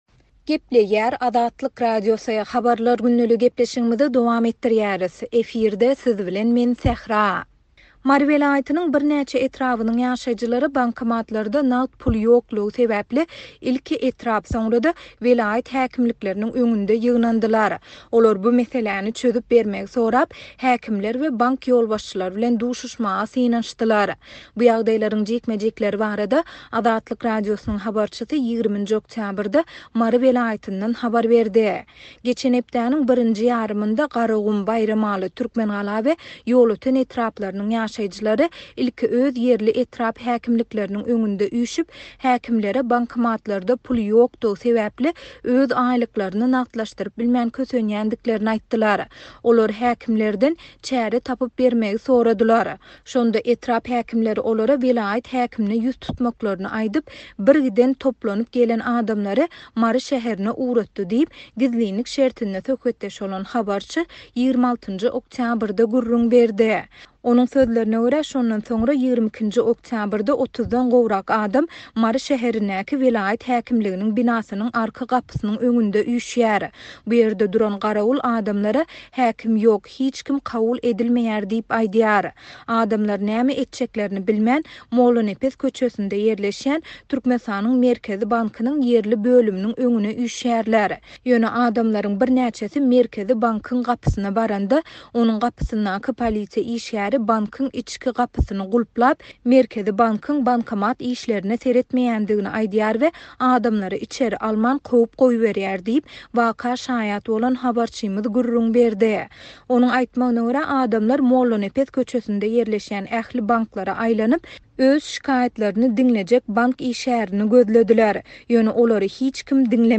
Mary welaýatynyň birnäçe etrabynyň ýaşaýjylary bankomatlarda nagt pul ýokdugy sebäpli ilki etrap, soňra-da welaýat häkimlikleriniň öňünde ýygnandylar. Olar bu meseläni çözüp bermegi sorap, häkimler we bank ýolbaşçylary bilen duşuşmaga synanyşdylar. Bu ýagdaýlaryň jikme-jikleri barada Azatlyk Radiosynyň habarçysy 20-nji oktýabrda Mary welaýatyndan habar berdi.